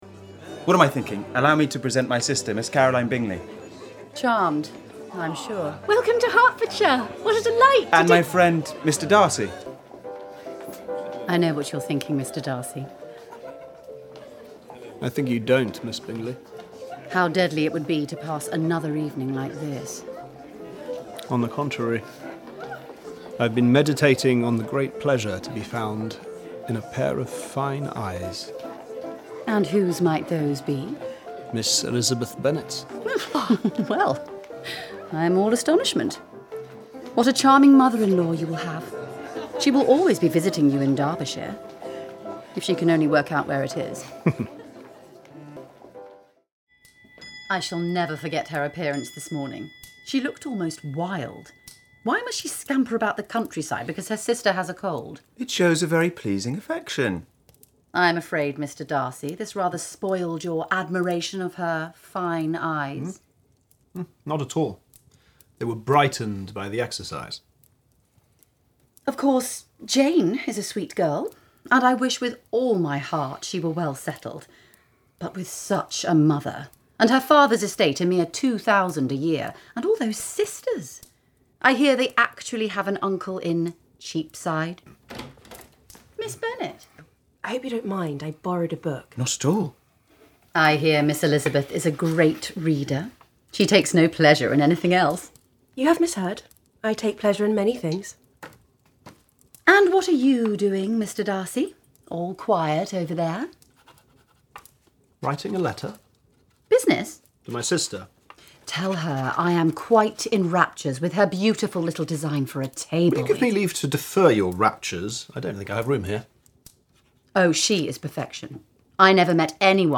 30/40's Neutral/RP,
Calm/Reassuring/Intelligent
Radio 4 Drama – Pride & Prejudice – Caroline Bingley